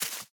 Minecraft Version Minecraft Version latest Latest Release | Latest Snapshot latest / assets / minecraft / sounds / block / azalea_leaves / break4.ogg Compare With Compare With Latest Release | Latest Snapshot